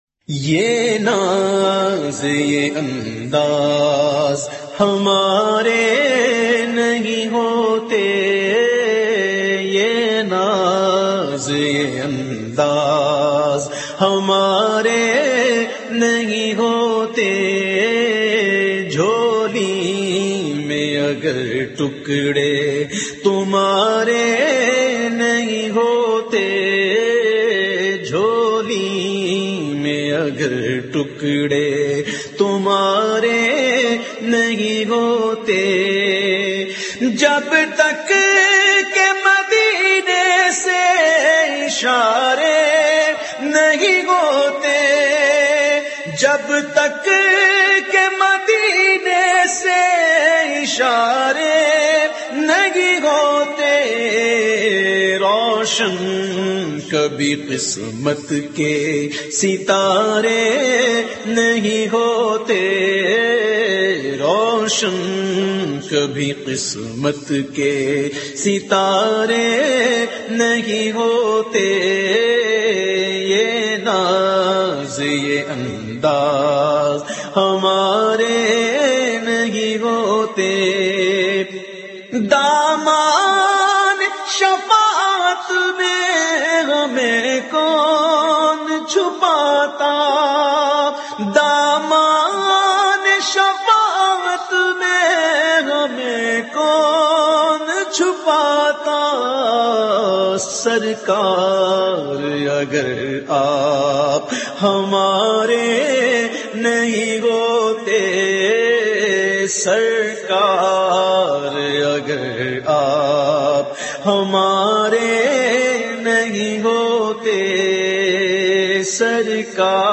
The Naat Sharif Yeh Naaz Yeh Andaaz recited by famous Naat Khawan of Pakistan owaise qadri.
نعت